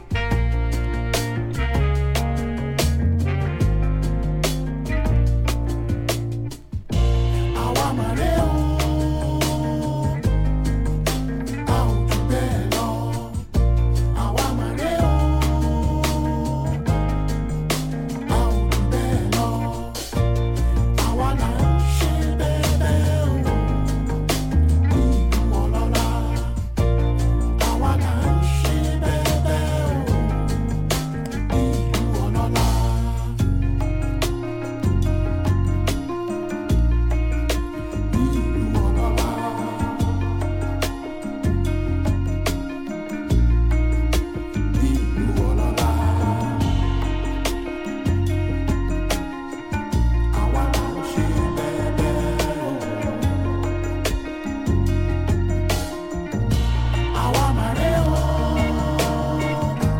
modern Afrobeat